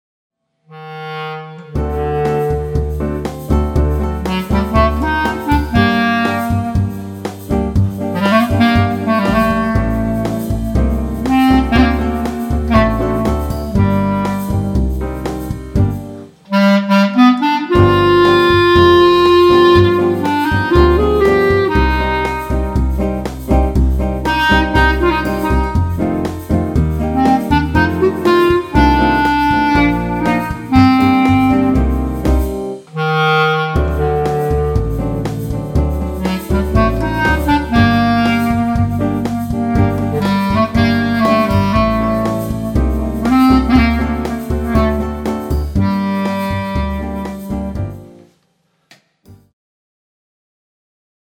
Soittoääniä - Sivu 2
Tuonkin kanssa on käynyt niin, että se kuulostaa tänä päivänä minulle puhelimen soimiselta, ei kivan kappaleen keskinkertaiselta esitykseltä.